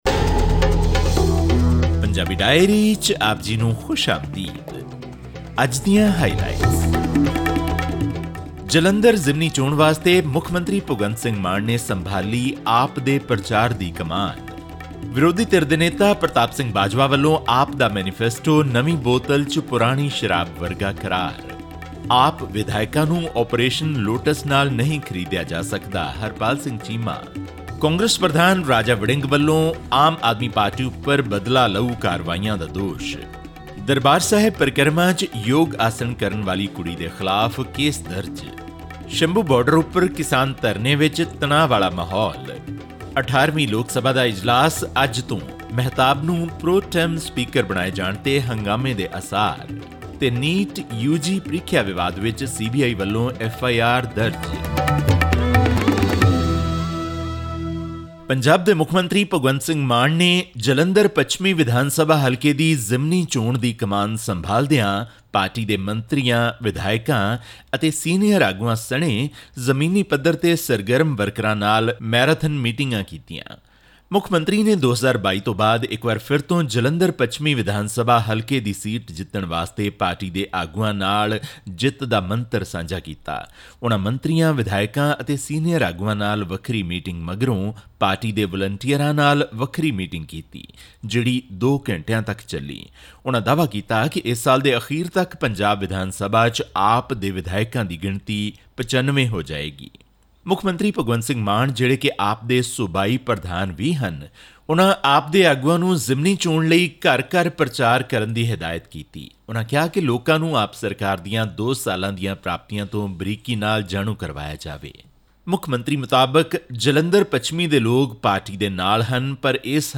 ਸ਼੍ਰੋਮਣੀ ਕਮੇਟੀ ਨੇ ਇਸ ਮਾਮਲੇ ਵਿੱਚ ਦੋ ਕਰਮਚਾਰੀਆਂ ਨੂੰ ਮੁਅੱਤਲ ਕਰ ਦਿੱਤਾ ਹੈ ਅਤੇ ਇੱਕ ਕਰਮਚਾਰੀ ਨੂੰ ਜੁਰਮਾਨਾ ਕਰਕੇ ਉਸ ਦਾ ਤਬਾਦਲਾ ਕਰ ਦਿੱਤਾ ਹੈ। ਹੋਰ ਵੇਰਵੇ ਲਈ ਸੁਣੋ ਇਹ ਆਡੀਓ ਰਿਪੋਰਟ